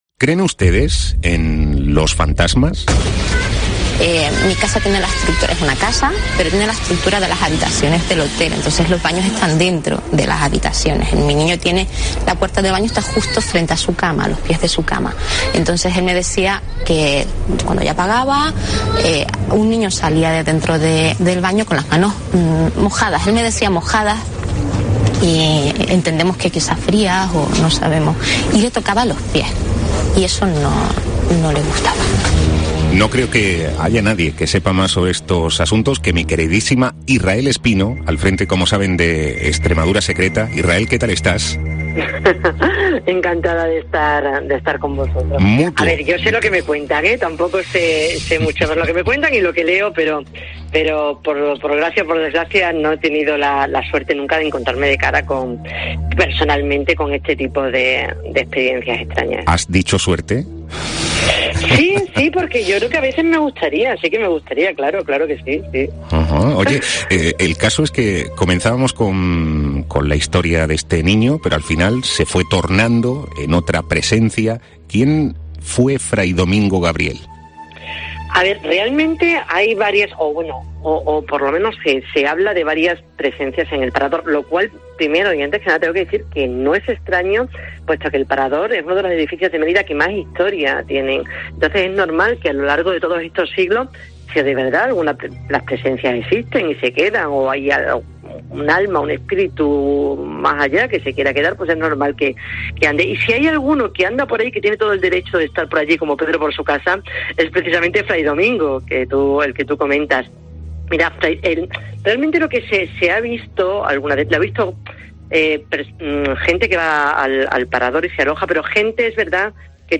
Por 'Herrera en COPE' en Extremadura ha pasado la mayor especialistas en estos asuntos de la región